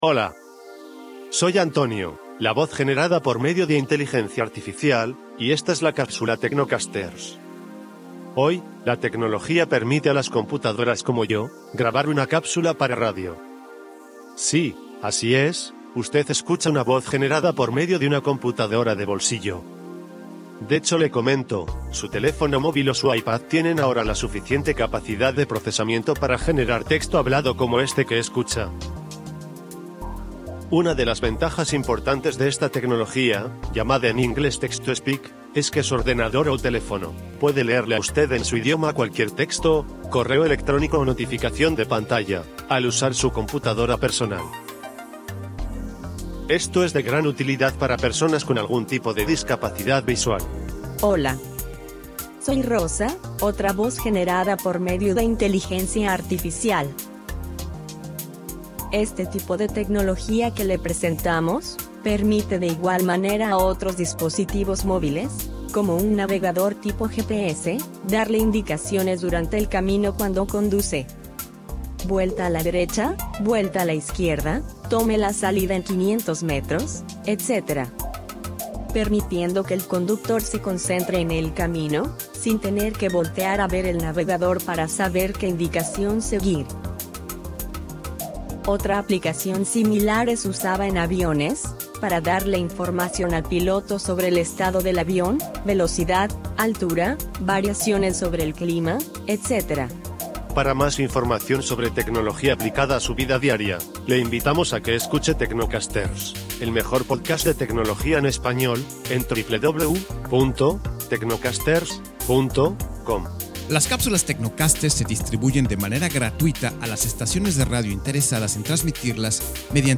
La primera Capsula TecnoCasters grabada totalmente con Locutores Electronicos!